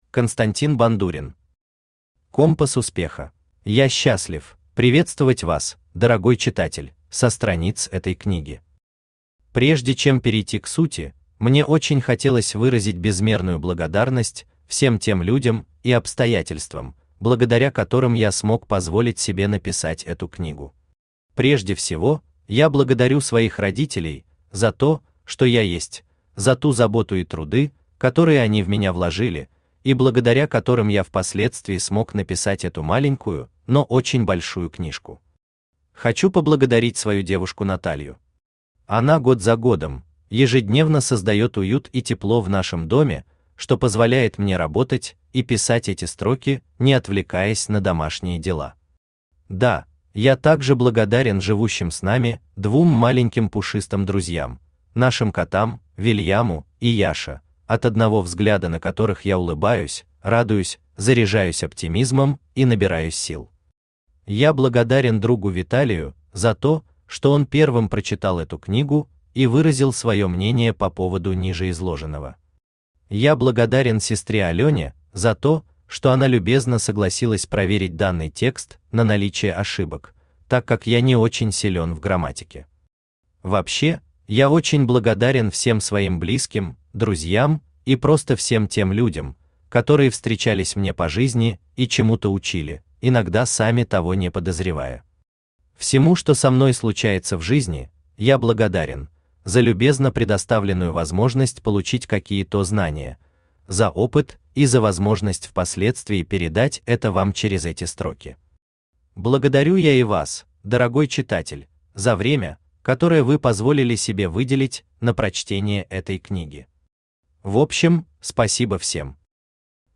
Аудиокнига Компас успеха | Библиотека аудиокниг
Aудиокнига Компас успеха Автор Константин Бандурин Читает аудиокнигу Авточтец ЛитРес.